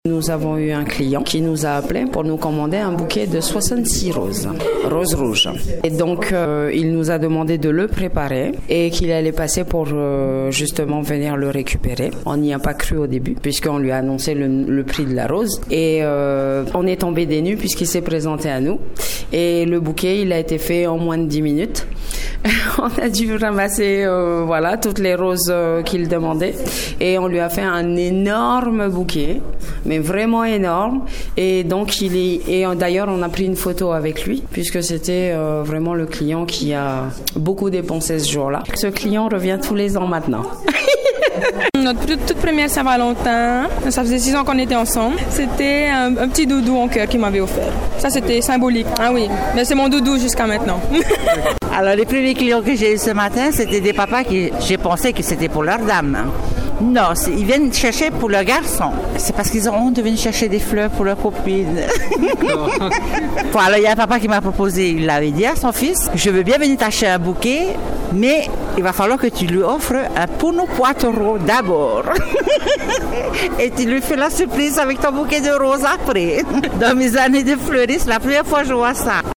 La rédaction de Radio 1 est allée à la rencontre des commençants de Papeete et de leurs clients afin de recueillir quelques anecdotes originales sur cette journée des amoureux.
Micro-trot-St-valentin.mp3